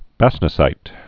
(băstnə-sīt)